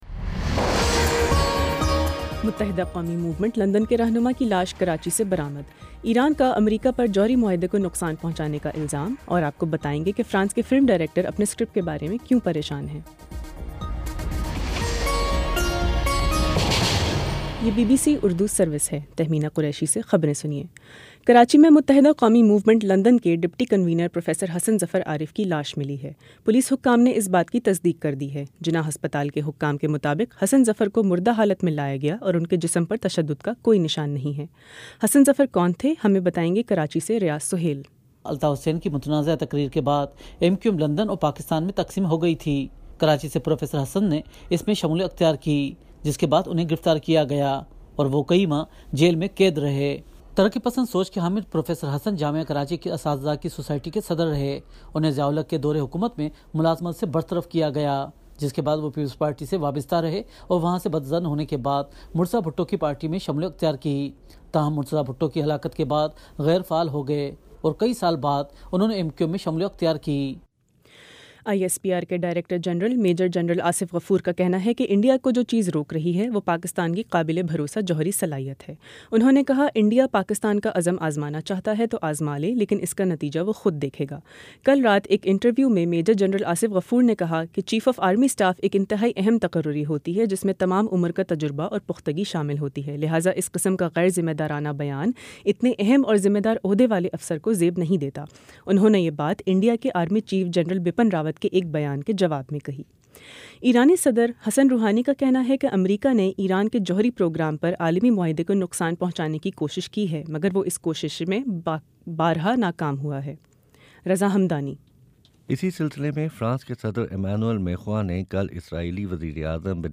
جنوری 14 : شام چھ بجے کا نیوز بُلیٹن